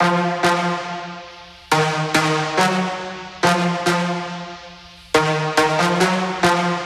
Index of /99Sounds Music Loops/Instrument Loops/Brasses